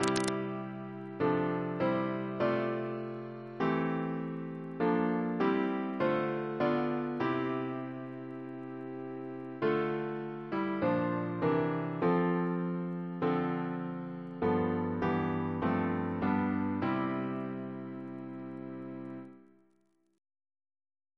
Double chant in C Composer: Chris Biemesderfer (b.1958) Note: for Psalm 121